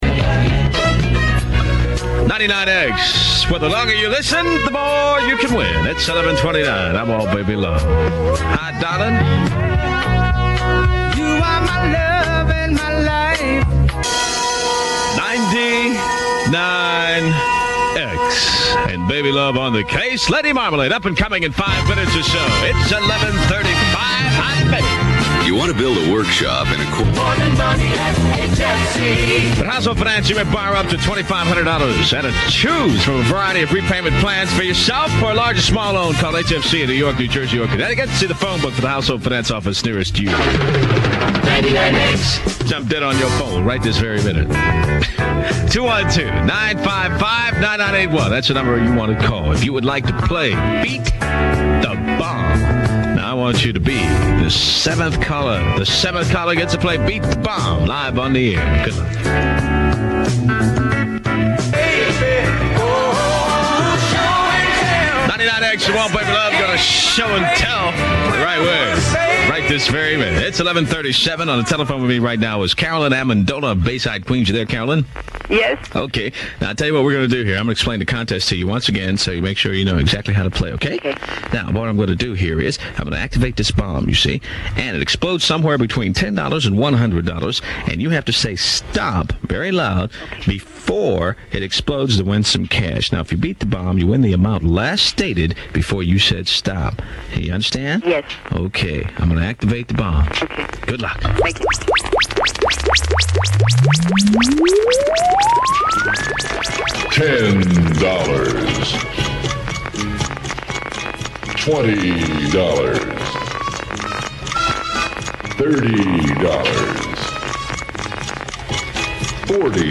99X Airchecks
And, it was always followed by the next song coming in loud after it.